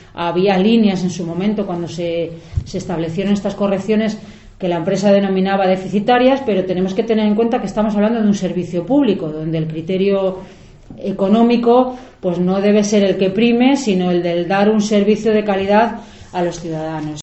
Yolanda Vázquez, portavoz PSOE. Moción Transporte urbano